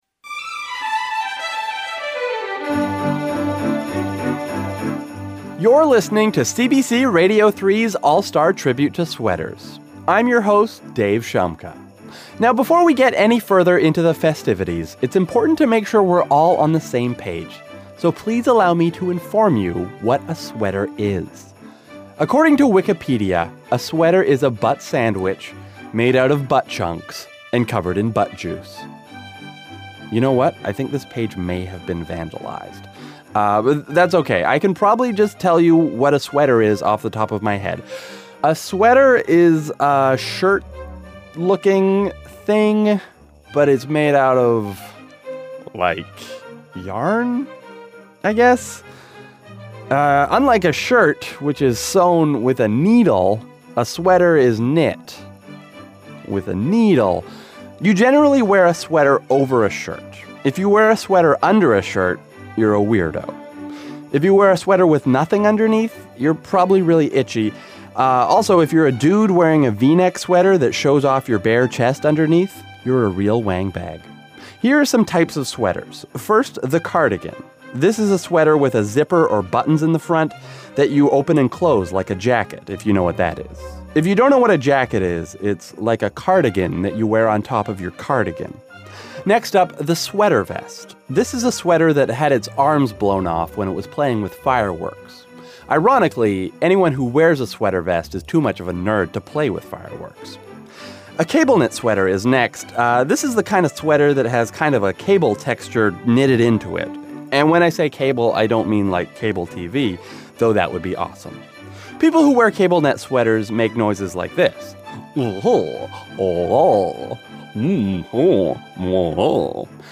I’m doing a holiday special for CBC Radio 3.